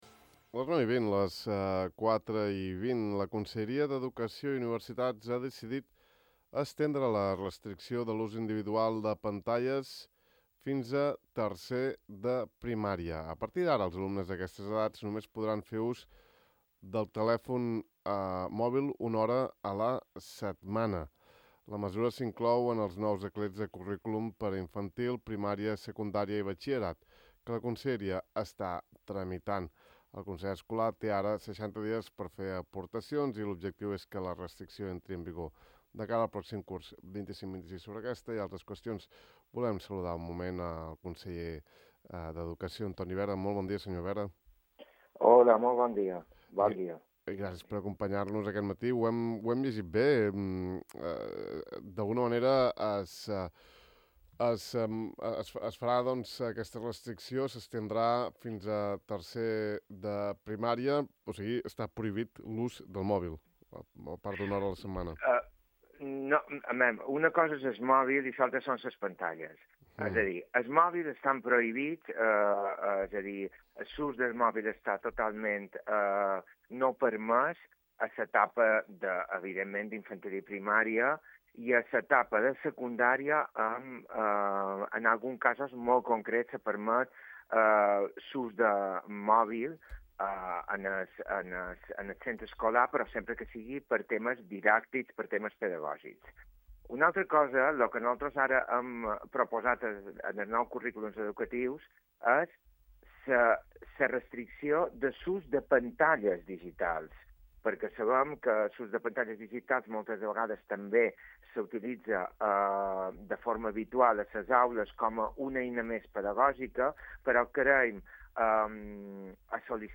El conseller balear d’Educació, Antoni Vera, ha afirmat a Ràdio Illa que espera que abans que conclogui la legislatura actual, a la primavera de 2027, estiguin concloses -o almenys iniciades- les obres d’unificació del CEIP Mestre Lluís Andreu.